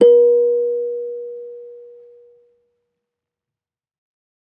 kalimba1_circleskin-B3-mf.wav